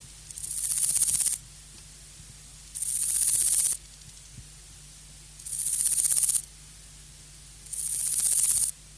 Акустические сигналы саранчового
Акустические сигналы: одиночный самец, Россия, Тува, Кызыл, запись
Температура записи 26-28° С.